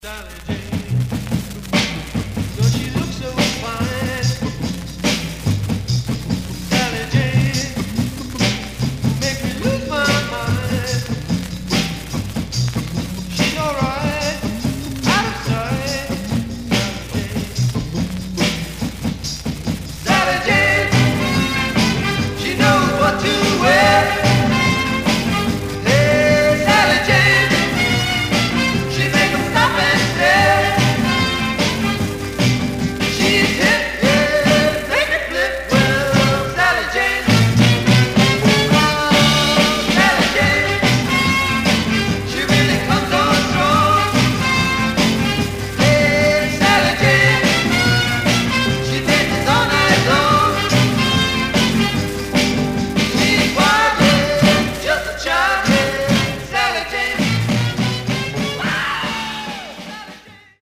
Surface noise/wear Stereo/mono Mono
Garage, 60's Punk